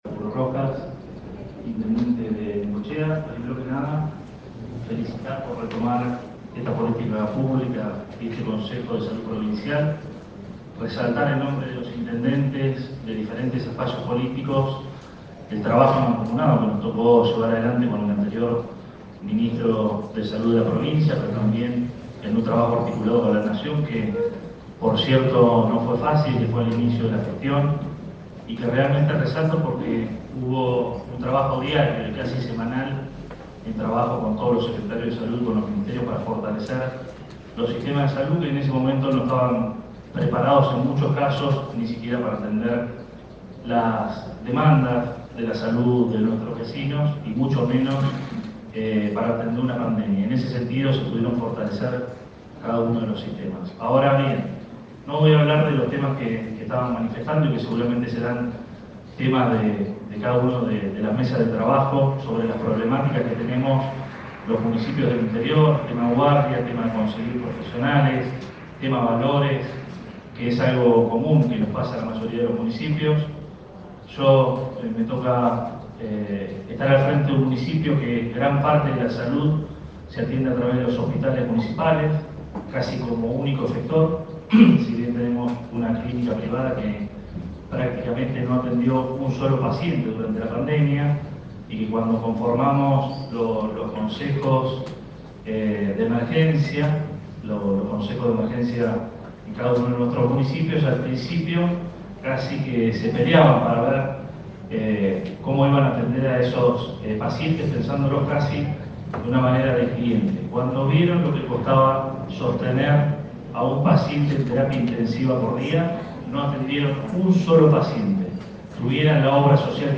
En el marco del Congreso Provincial de Salud, que tiene lugar hasta mañana en el Hotel UTHGRA de Mar del Plata, el intendente Arturo Rojas expuso ante los presentes esta tarde, puntualmente en la Asamblea del Consejo a través de la cual quedó constituido que Necochea tenga representación en la mesa ejecutiva.
21-04-AUDIO-Arturo-Rojas.mp3